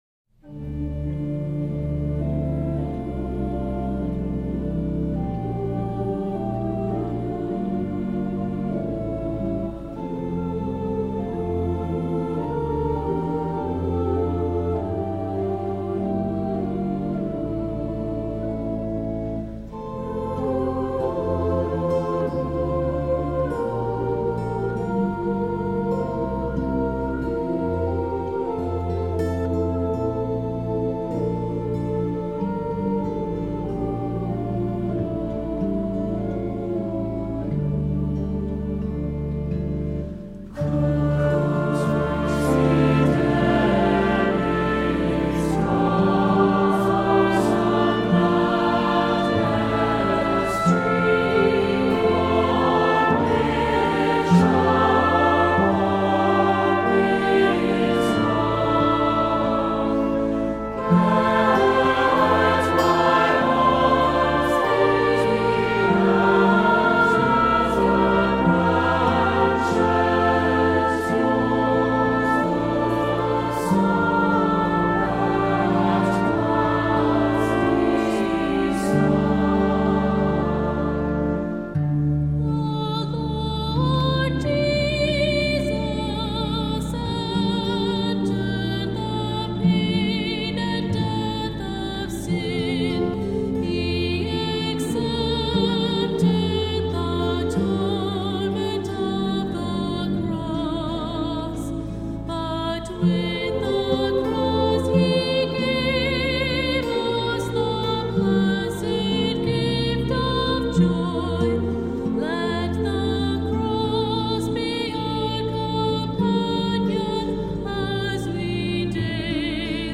Voicing: Assembly, cantor